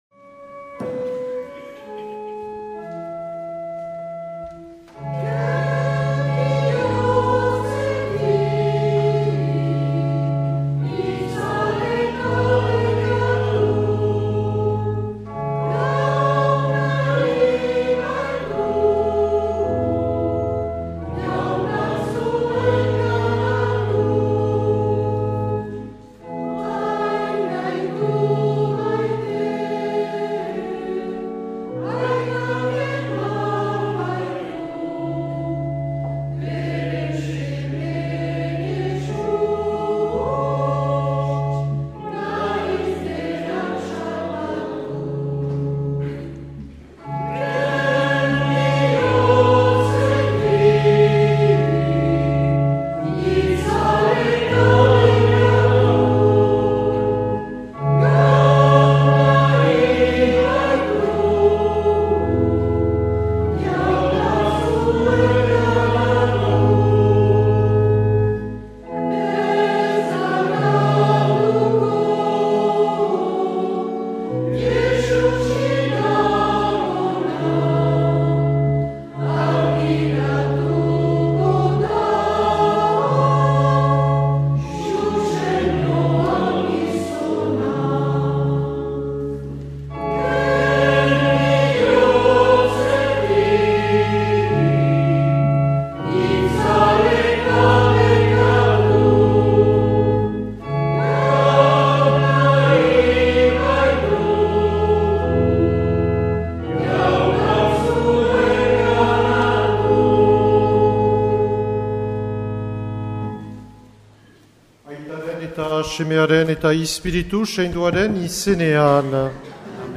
2023-03-19 Garizumako 4. Igandea A - Itsasu
Accueil \ Emissions \ Vie de l’Eglise \ Célébrer \ Igandetako Mezak Euskal irratietan \ 2023-03-19 Garizumako 4.